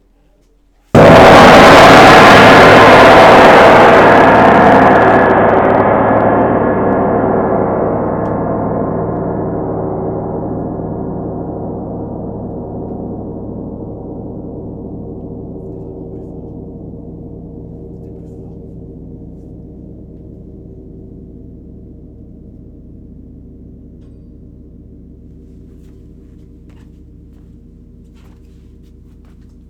Grand_fortissimo.wav